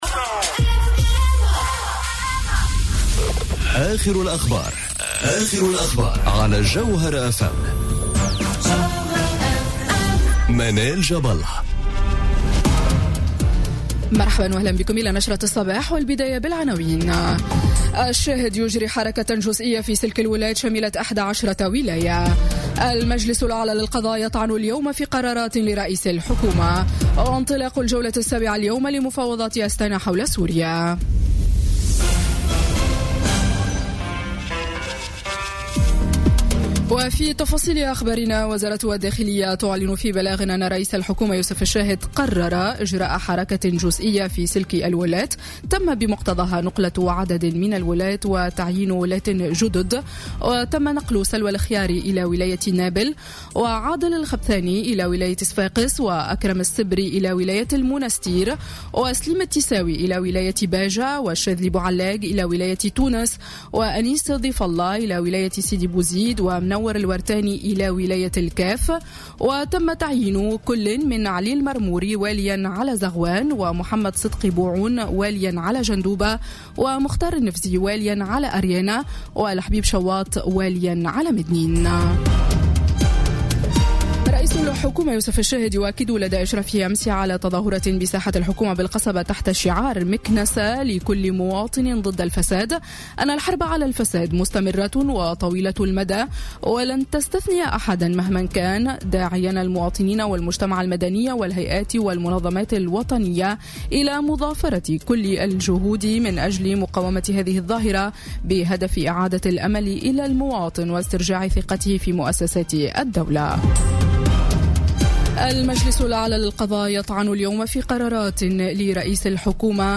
Journal Info 07h00 du lundi 30 Octobre 2017